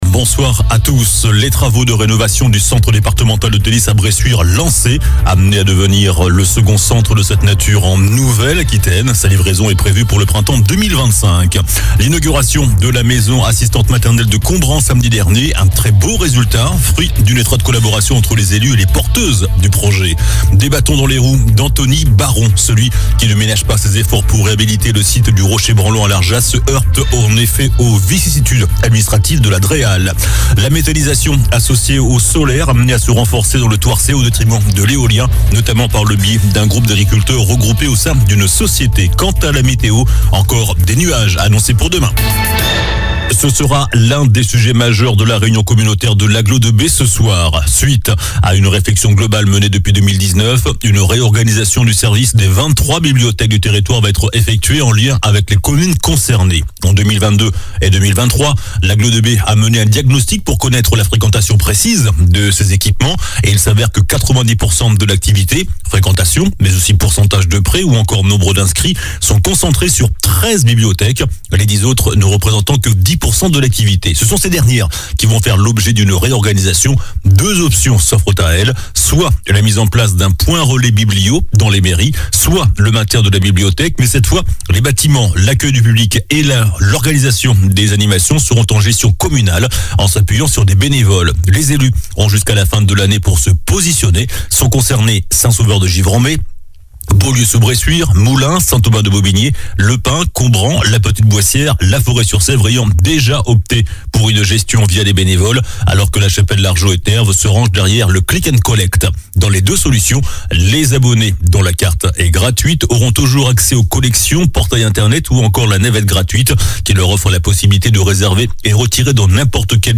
infos locales